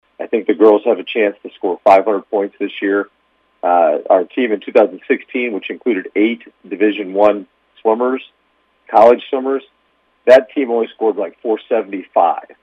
INTERVIEW POST CHAMPIONSHIP